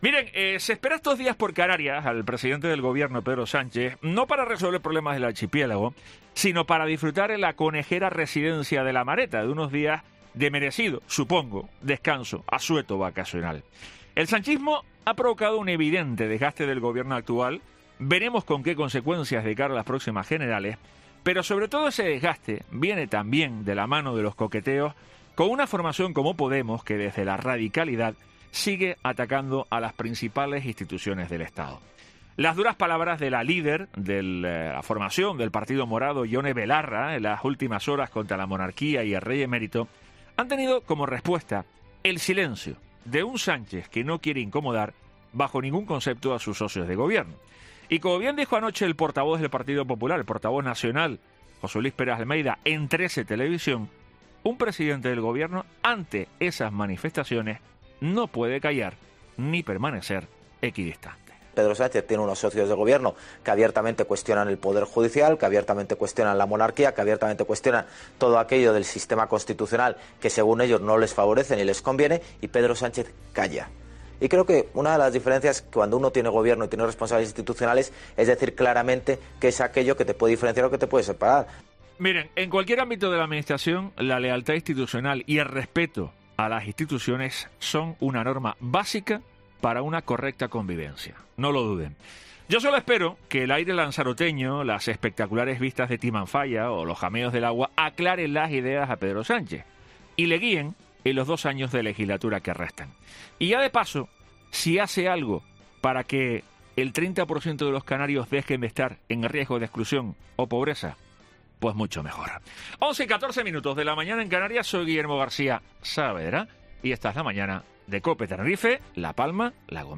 editorial